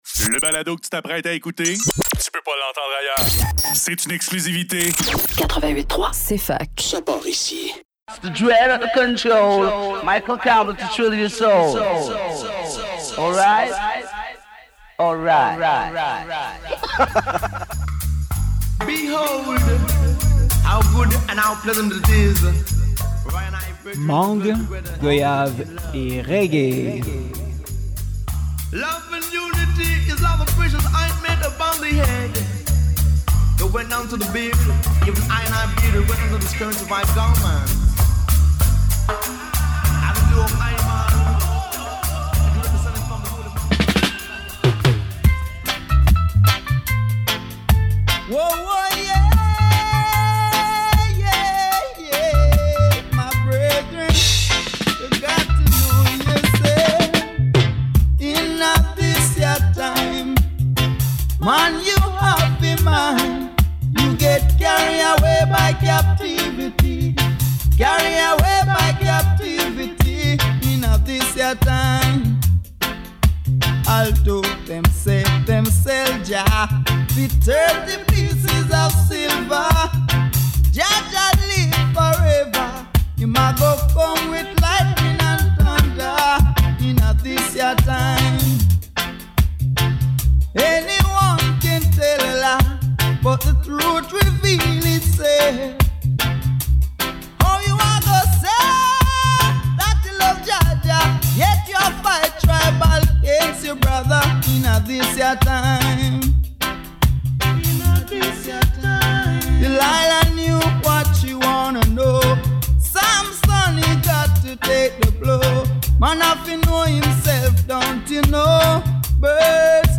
Du son lourd, avec beaucoup de basses&hellip